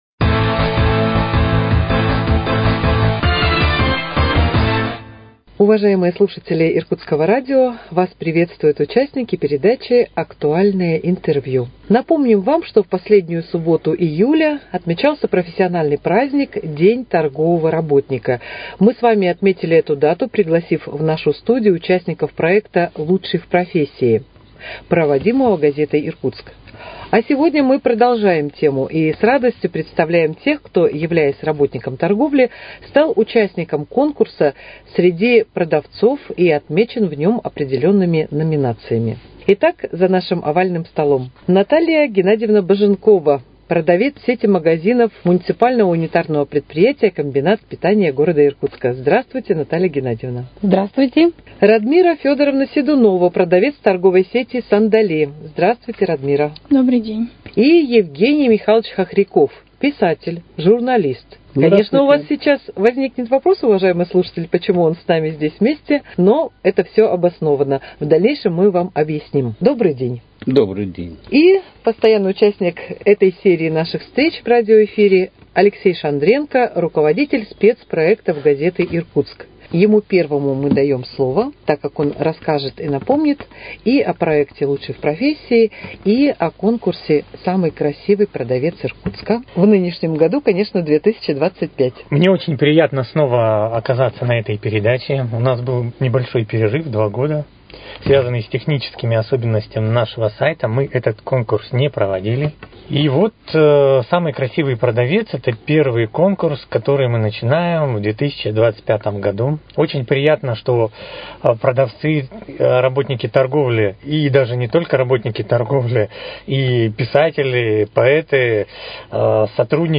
Актуальное интервью: Беседа ко Дню торгового работника